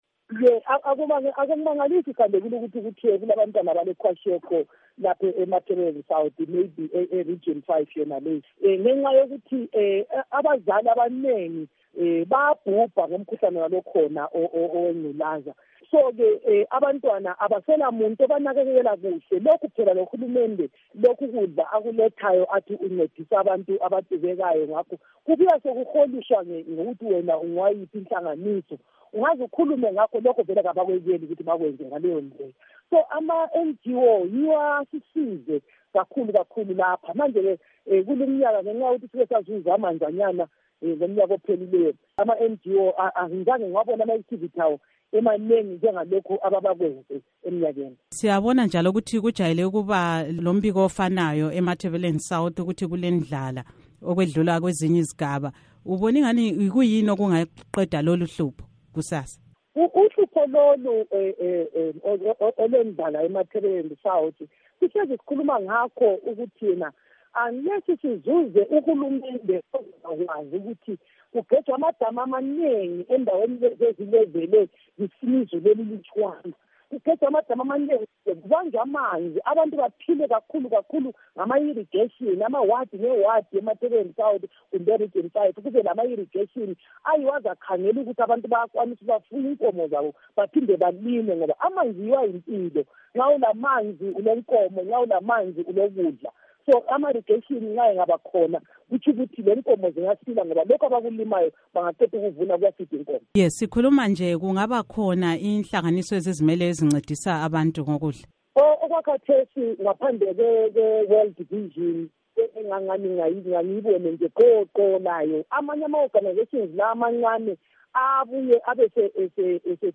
Ingxoxo LoSenator Sithembile Mlotshwa